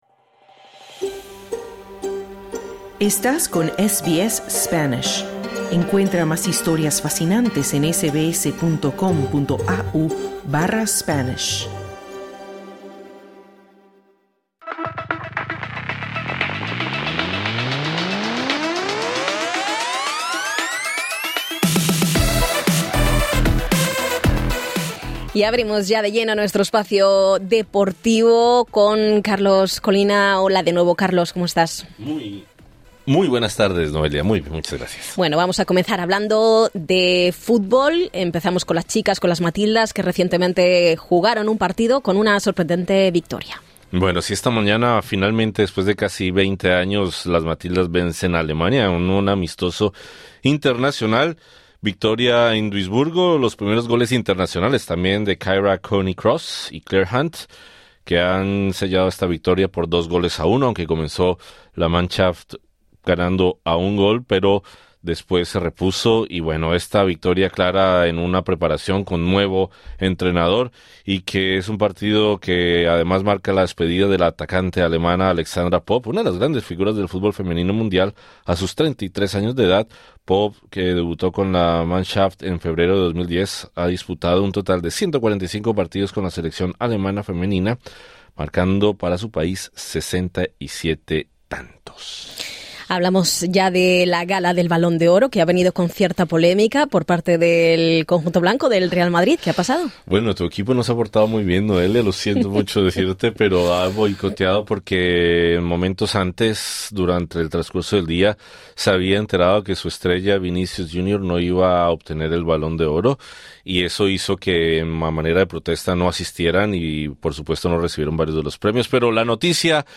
Escucha el informe deportivo en el podcast localizado en la parte superior de esta página.